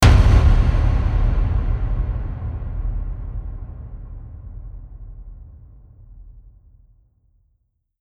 Jumpscare_07.wav